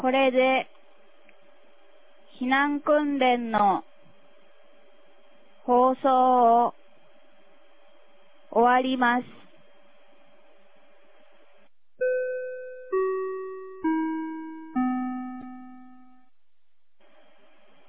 2022年11月06日 09時02分に、南国市より放送がありました。